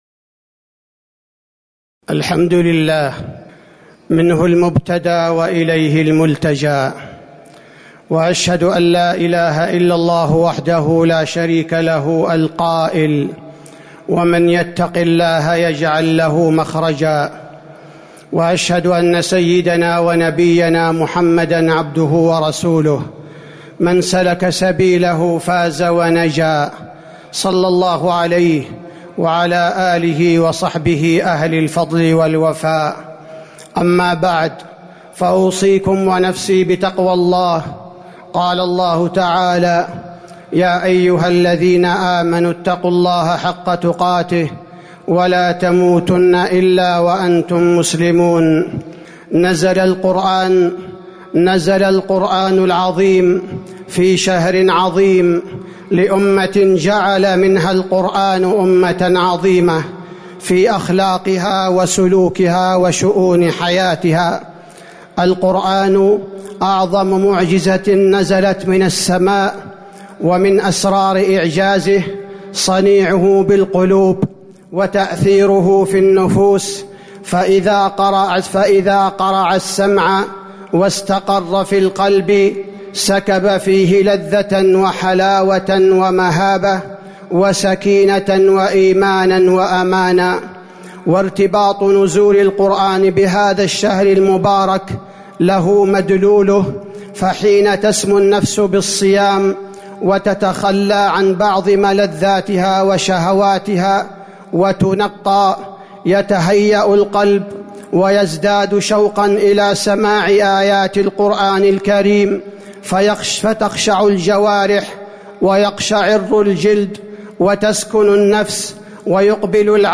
تاريخ النشر ٧ رمضان ١٤٤٣ هـ المكان: المسجد النبوي الشيخ: فضيلة الشيخ عبدالباري الثبيتي فضيلة الشيخ عبدالباري الثبيتي شهر رمضان الذي أنزل فيه القرآن